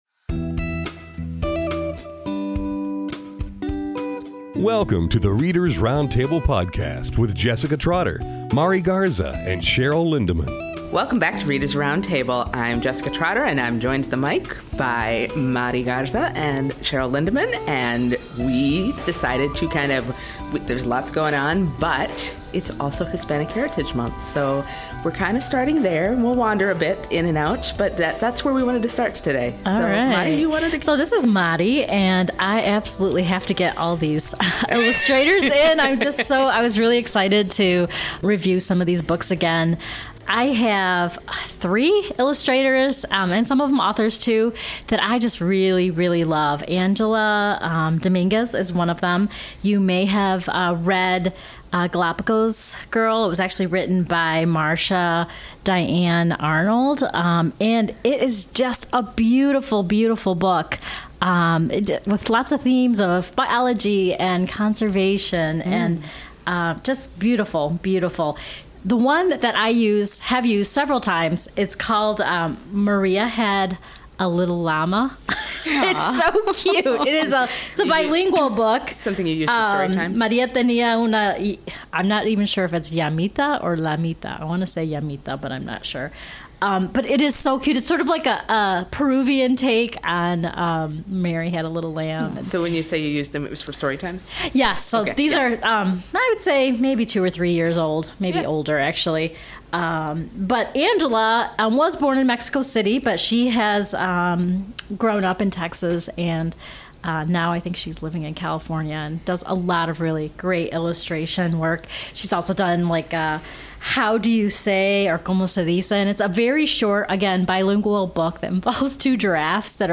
CADL's Collection Development Specialists chat about Hispanic Heritage Month picks, a few titles they’re reading now and a few they're looking forward to.